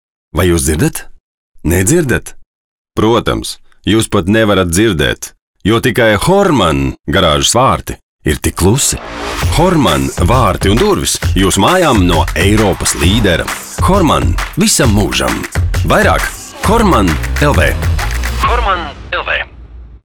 RADIO REKLĀMAS